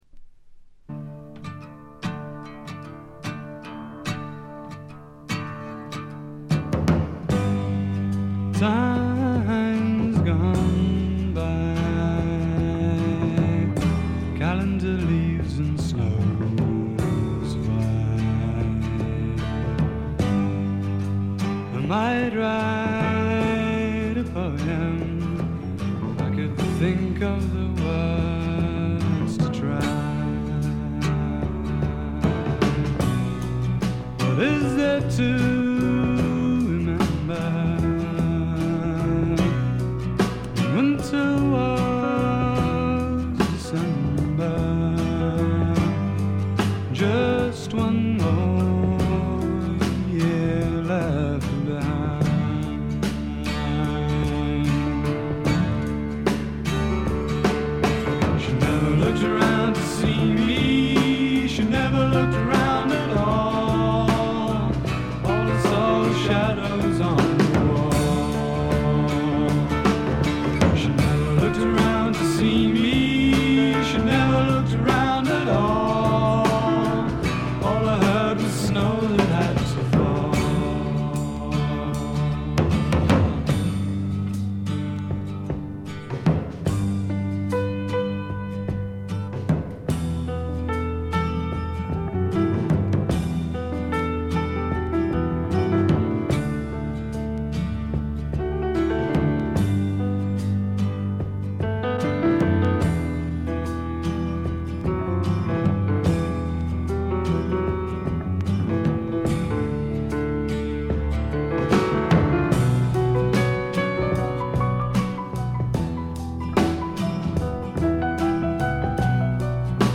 ごくわずかなノイズ感のみ。
音の方はウッドストック・サウンドに英国的な香りが漂ってくるという、この筋の方にはたまらないものに仕上がっています。
試聴曲は現品からの取り込み音源です。